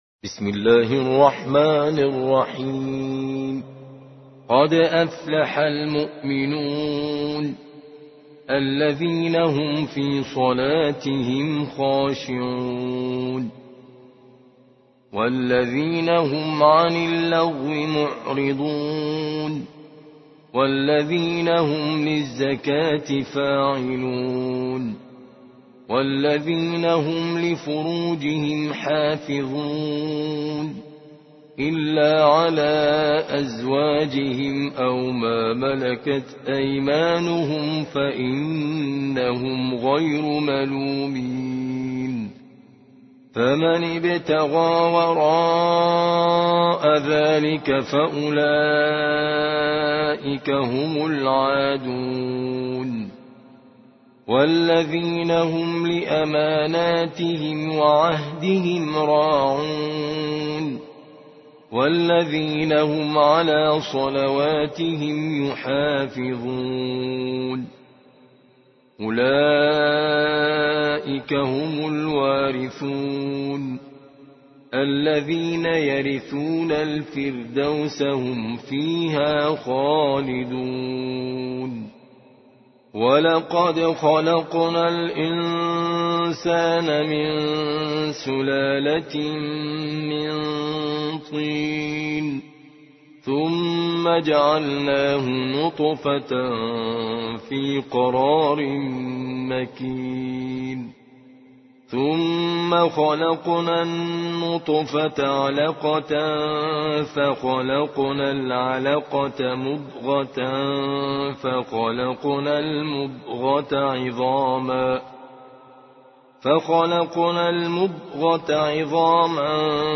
23. سورة المؤمنون / القارئ